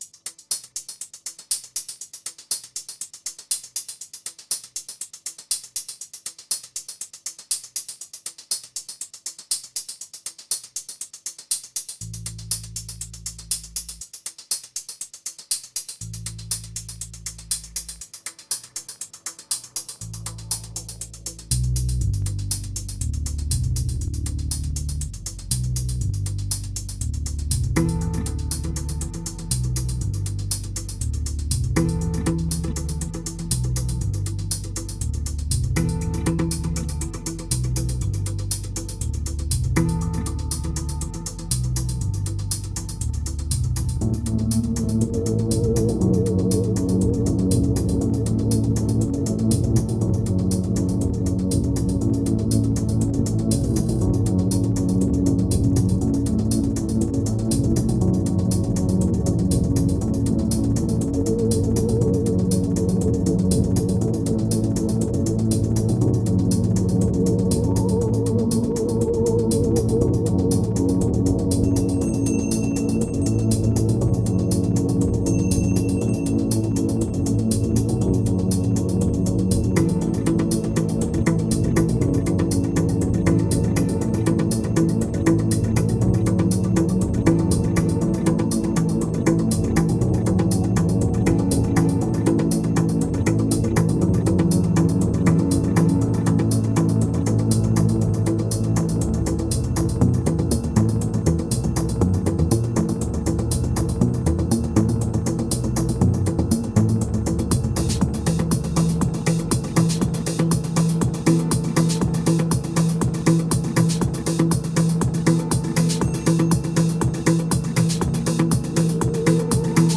2141📈 - 7%🤔 - 120BPM🔊 - 2012-05-08📅 - -104🌟